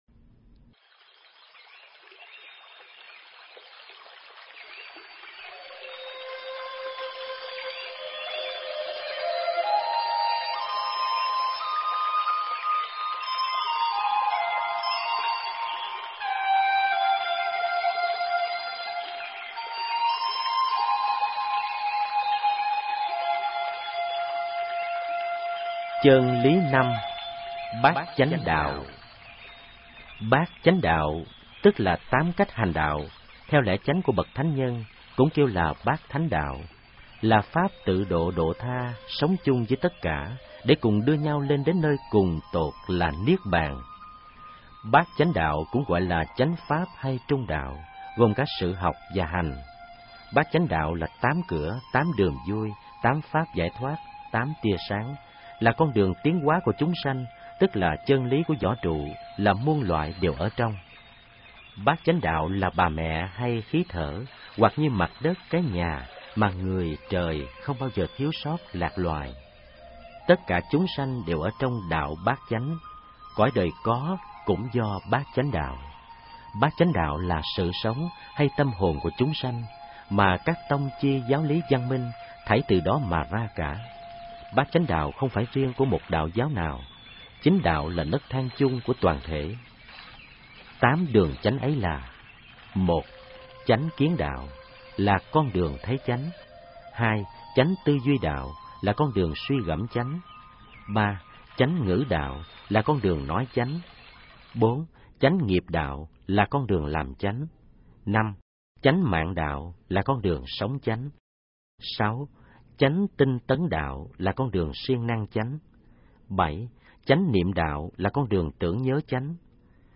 Nghe sách nói chương 05. Bát Chánh Đạo